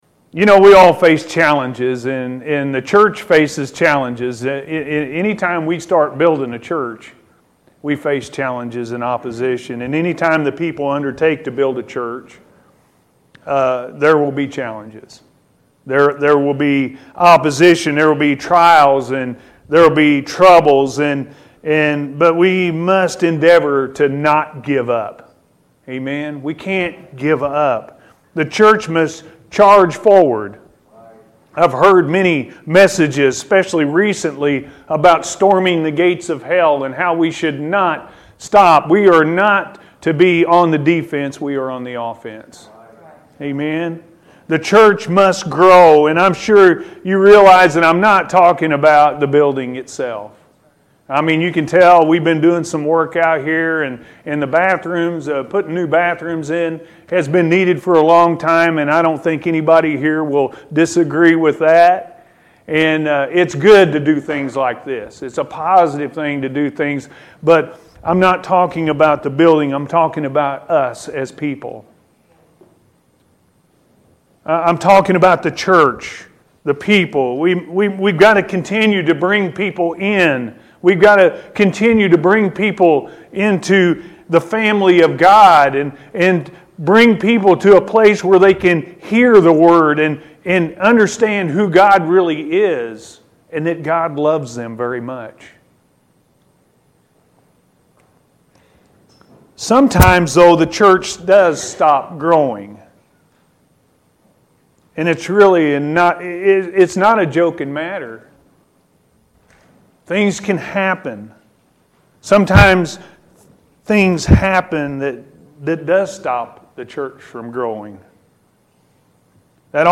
The Church Cannot Give Up-A.M. Service